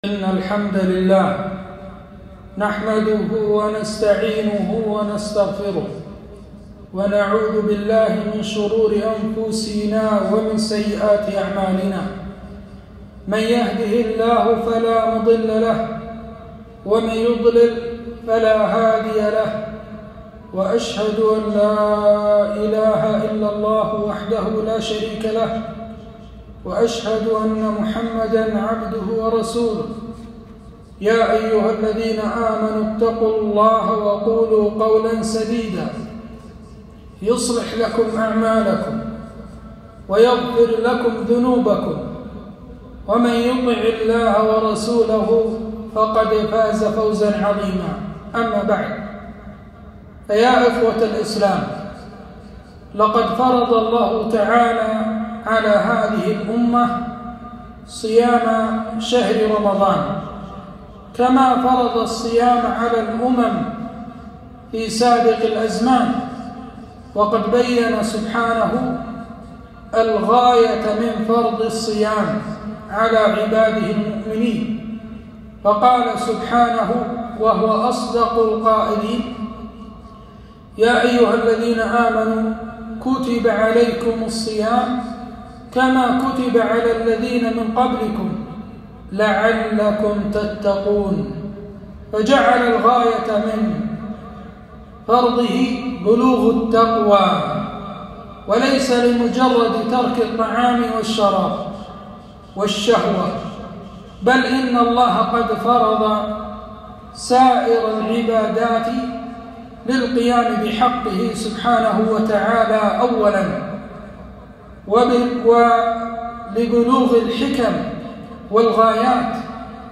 خطبة - يا باغي الخير أقبل